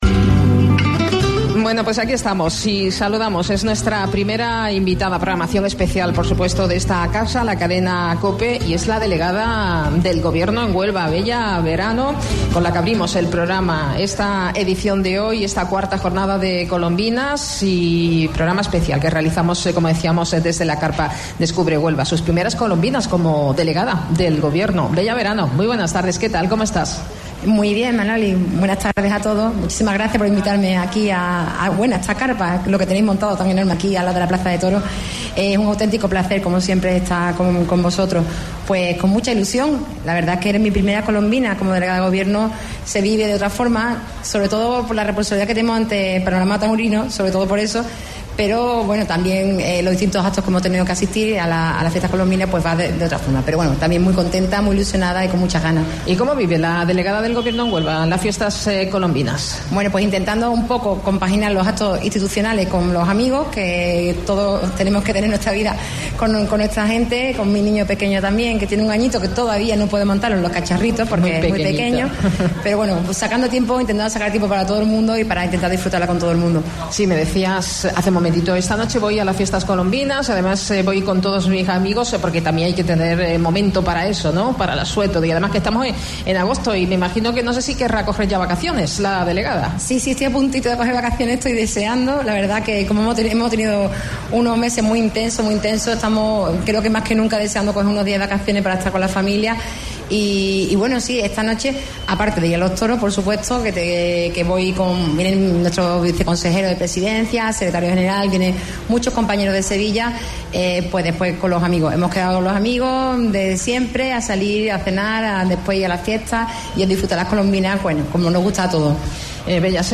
AUDIO: Delegada Gobierno Junta en Huelva en Programa especial COLOMBINAS de COPE